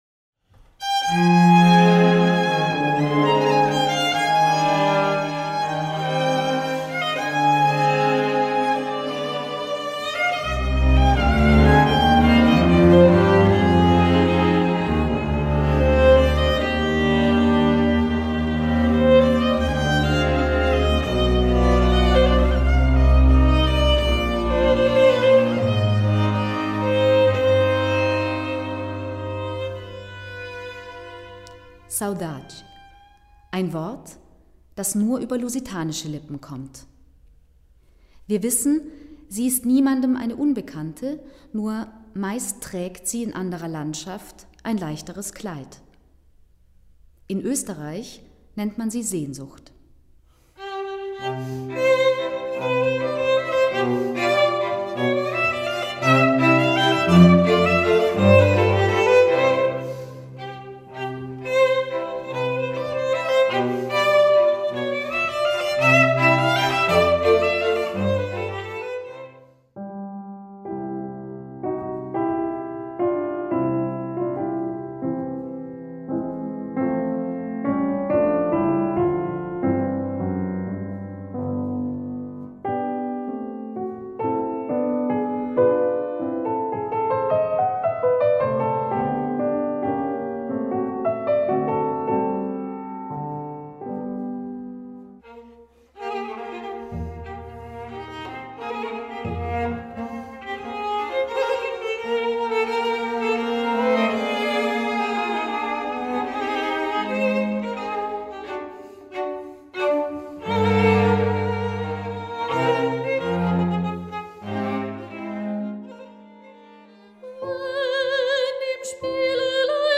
Palais Kuenburg-Langenhof
Violin
Viola
Cello
Double Bass
Piano
Soprano